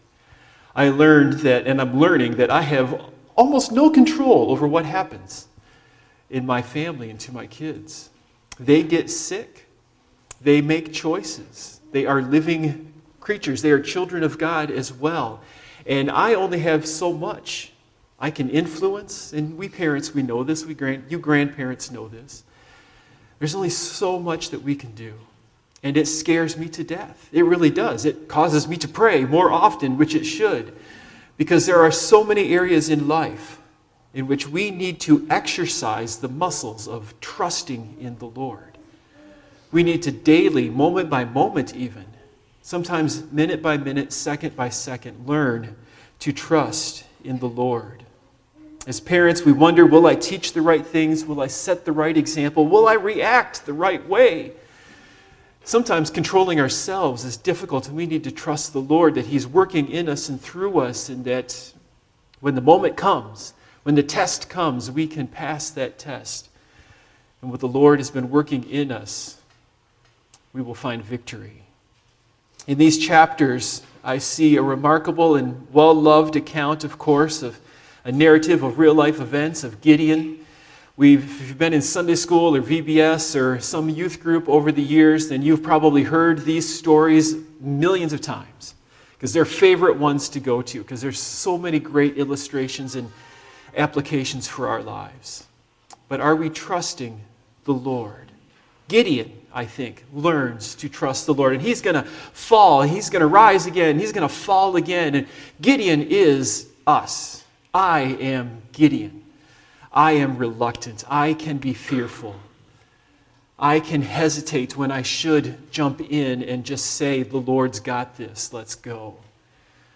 Passage: Judges 6, 7, 8 Service Type: Morning Worship Topics